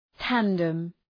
Shkrimi fonetik {‘tændəm}